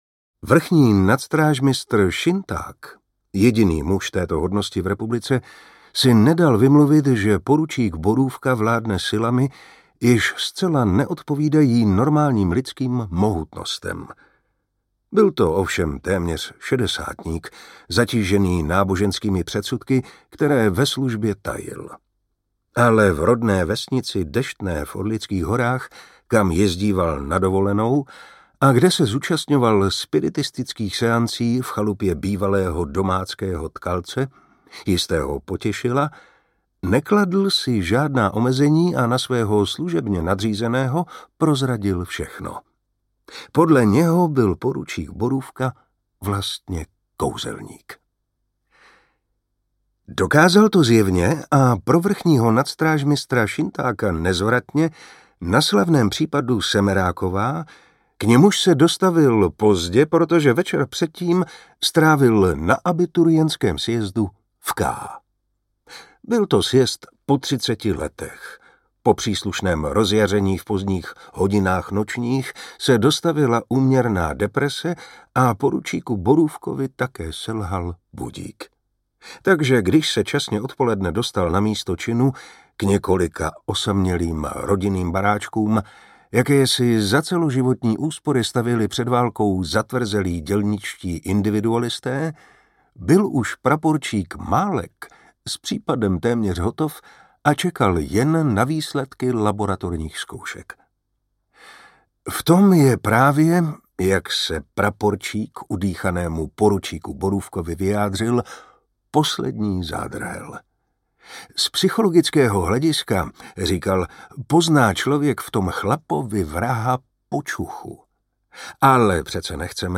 Smutek poručíka Borůvky audiokniha
Ukázka z knihy
• InterpretMartin Preiss